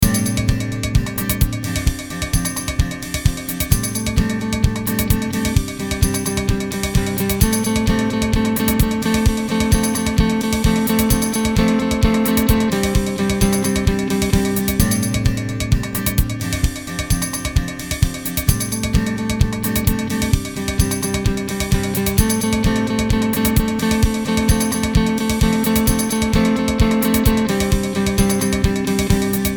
レトロな雰囲気が漂うシティポップ風の着信音。テンポ130の軽快なリズムが、どこか懐かしい記憶を呼び起こします。